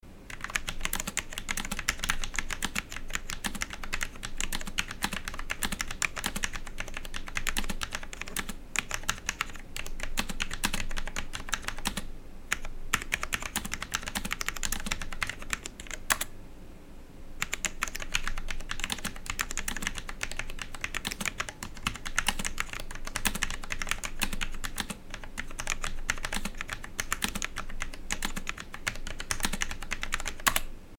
بررسی کیبورد کولرمستر SK650 در شهر سخت‌افزار به همراه تست کارایی و صدای سوییچ‌های Cherry MX Low Profile Red
در فایل صوتی زیر می‌توانید صدای حاصل از تایپ با این کیبورد را که توسط میکروفون با کیفیت بالا از فاصله 10 سانتی‌متری ضبط شده است، بشنوید: